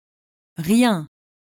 When pronouncing rien, the final -n is completely silent.
rien – IPA pronunciation /ʀjɛ̃/